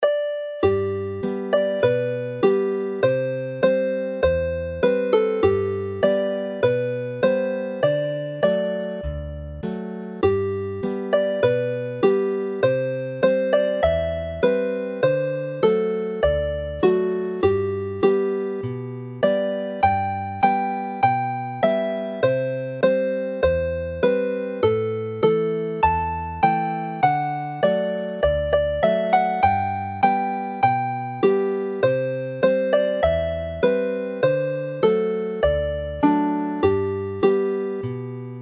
The song Y Gelynnen appears earlier in this collection in a different version; this one is a little more relaxed whereas the jaunty Sbonc Bogel (Belly jerk) which finishes the set is a lively jig.
Play the melody slowly